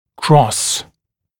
[krɔs][крос]пересекать, пересекаться, перекрещиваться